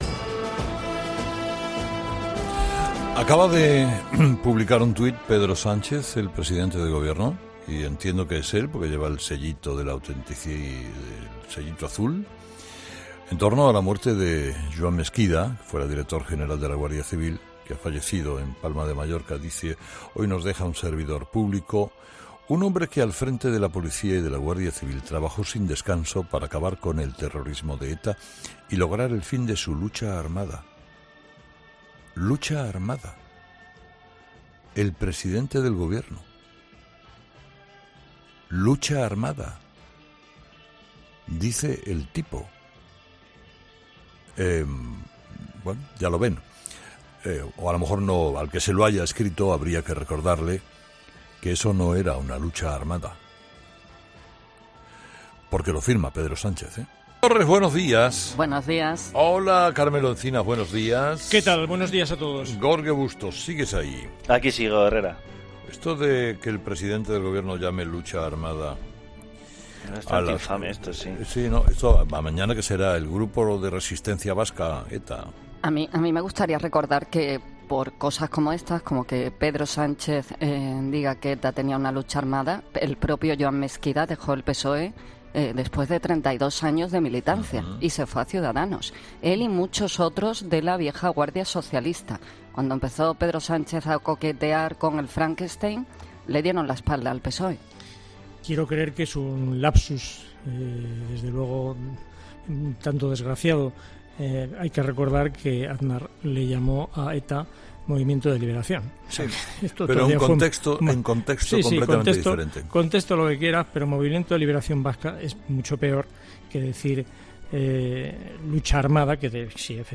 Carlos Herrera y sus tertulianos critican que el presidente se refiera así al régimen de terror que sembró la banda terrorista.